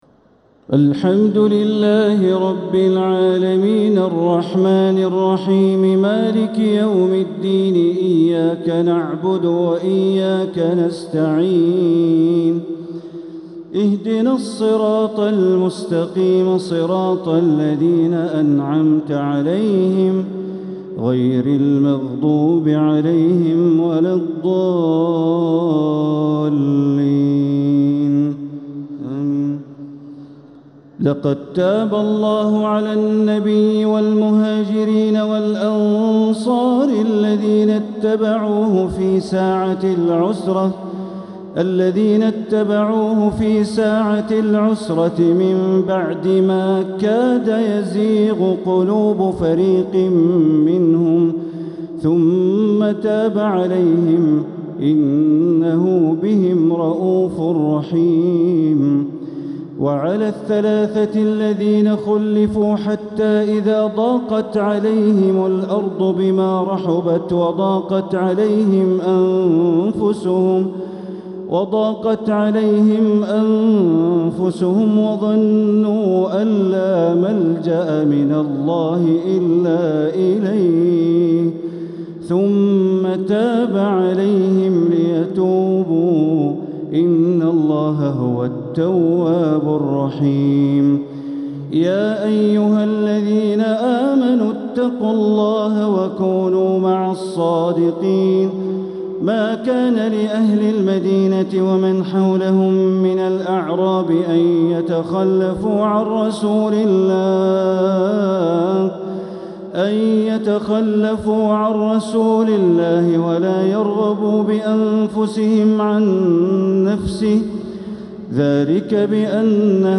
تراويح ليلة 14 رمضان 1447هـ من سورتي التوبة (117) ويونس (1-25) | Taraweeh 14th night Ramadan 1447H Surah Al-Tawbah and Yunus > تراويح الحرم المكي عام 1447 🕋 > التراويح - تلاوات الحرمين